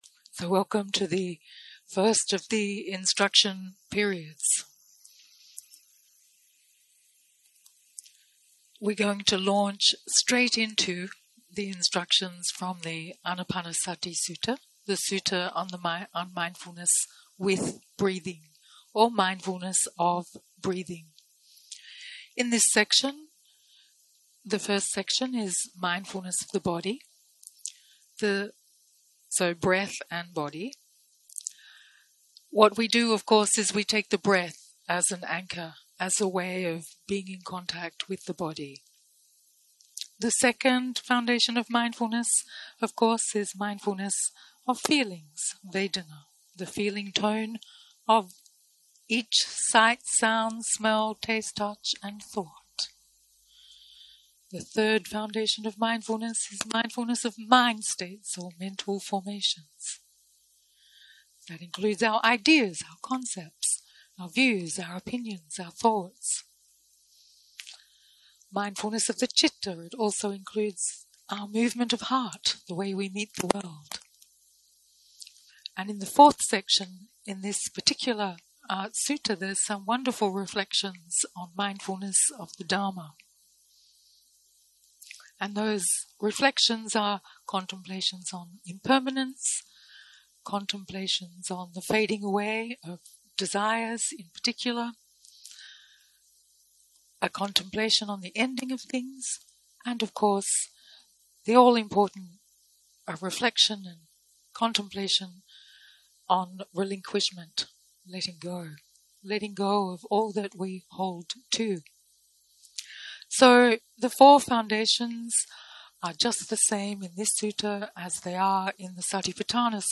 יום 1 - הקלטה 1 - ערב - הנחיות למדיטציה